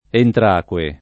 Entraque [ entr # k U e ]